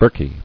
[birk·ie]